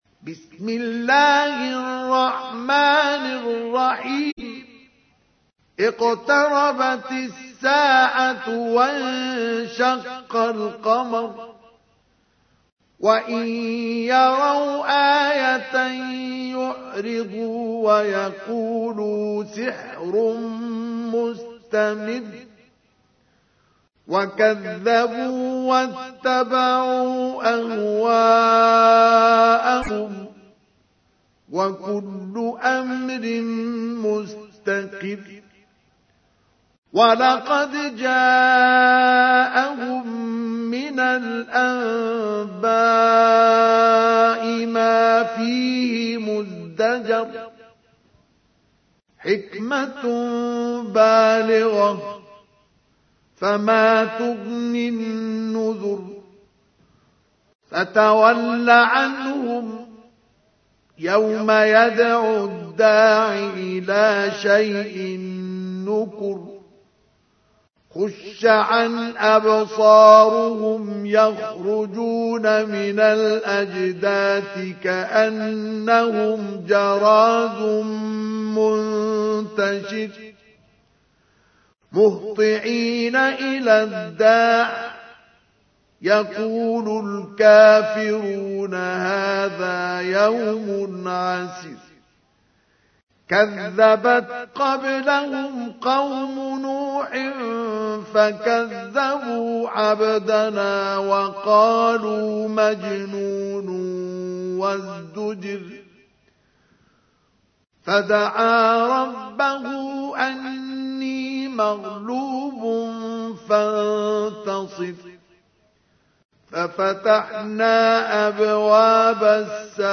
تحميل : 54. سورة القمر / القارئ مصطفى اسماعيل / القرآن الكريم / موقع يا حسين